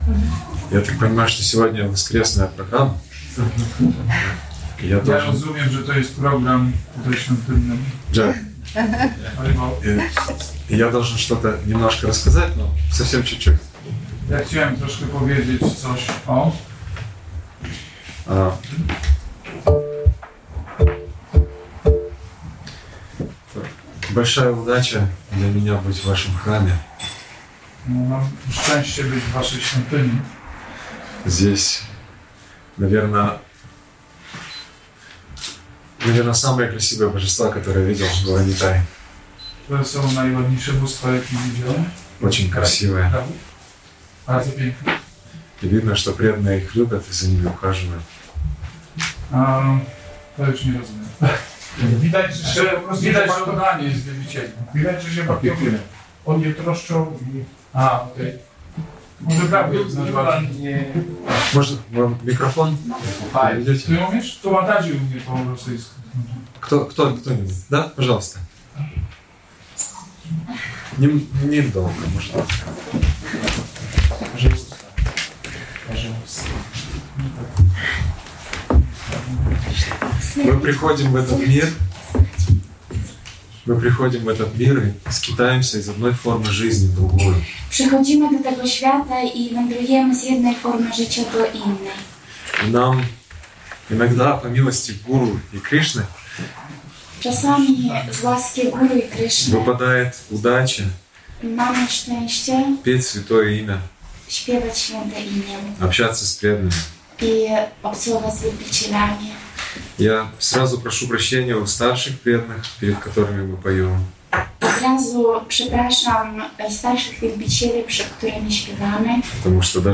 Wykład jaki odbył się 6 lipca 2025 roku.